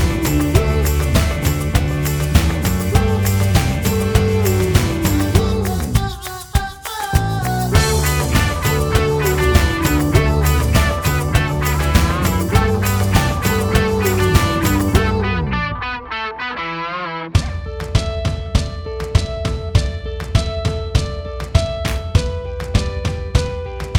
no Backing Vocals Indie / Alternative 3:13 Buy £1.50